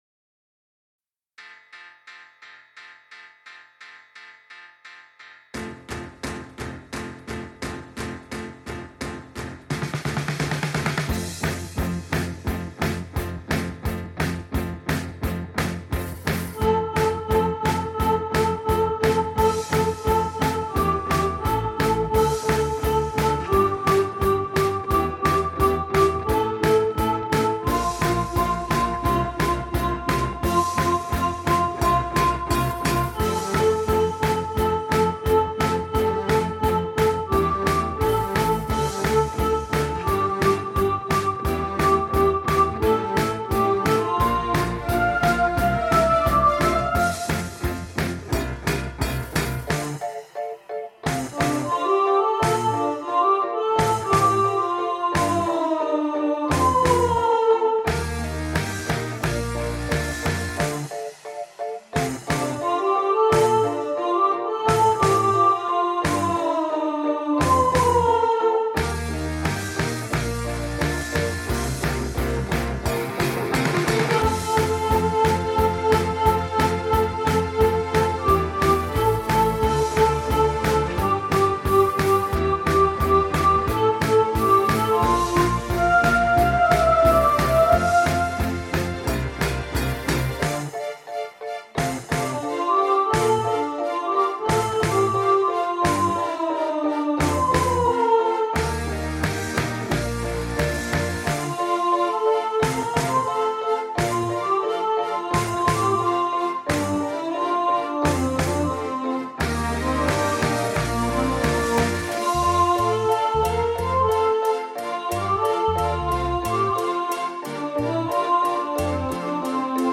Mr Blue Sky – Soprano 2 | Ipswich Hospital Community Choir
Mr-Blue-Sky-Soprano-2.mp3